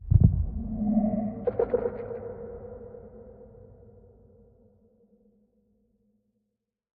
Minecraft Version Minecraft Version 25w18a Latest Release | Latest Snapshot 25w18a / assets / minecraft / sounds / mob / warden / nearby_closer_3.ogg Compare With Compare With Latest Release | Latest Snapshot